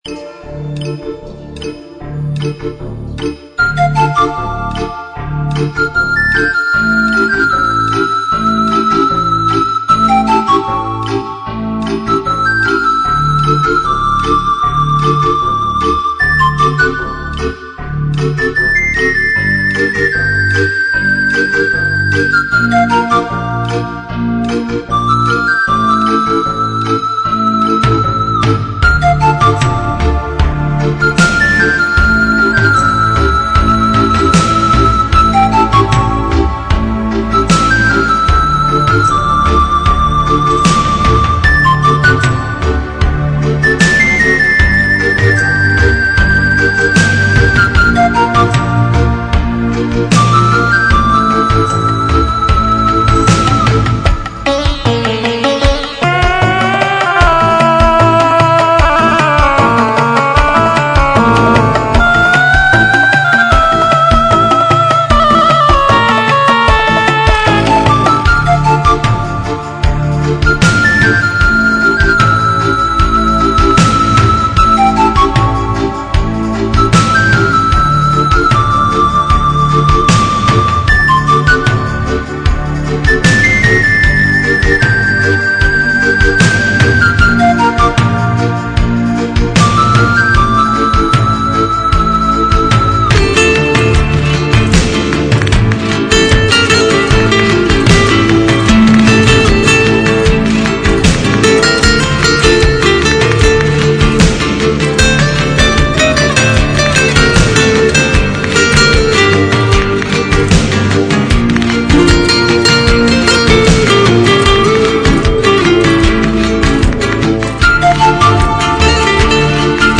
а тут флейта